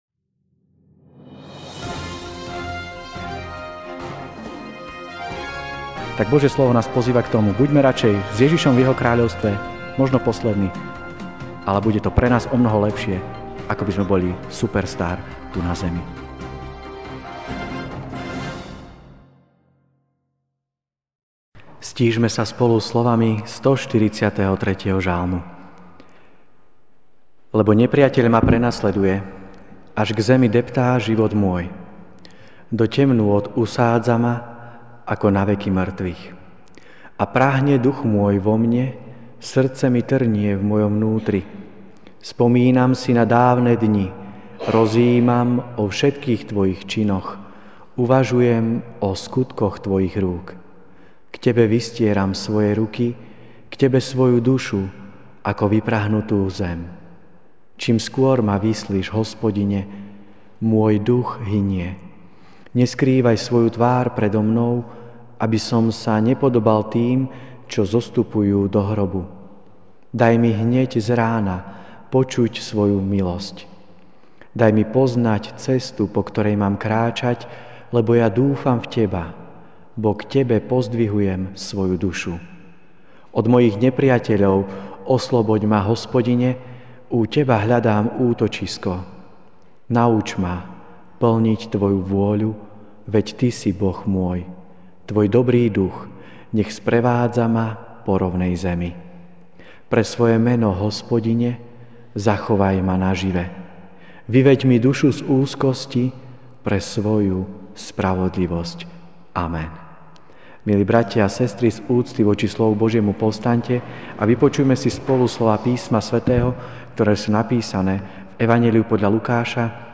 okt 23, 2016 Radšej poslední MP3 SUBSCRIBE on iTunes(Podcast) Notes Sermons in this Series Večerná kázeň: (Lk. 13, 22-30) A chodil po mestách a dedinách, učil a pobral sa do Jeruzalema.